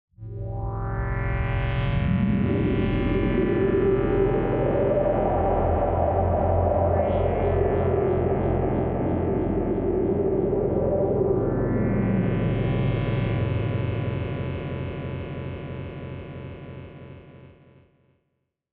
دانلود آهنگ باد 14 از افکت صوتی طبیعت و محیط
دانلود صدای باد 14 از ساعد نیوز با لینک مستقیم و کیفیت بالا
جلوه های صوتی